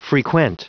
Prononciation du mot frequent en anglais (fichier audio)
Prononciation du mot : frequent